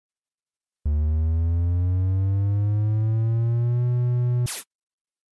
Play, download and share Fuel Engines 1 Among Us original sound button!!!!
fuel-engines-among-us-canister-task-complete-sound-effect-for-editing-mp3cut_8ytfoTv.mp3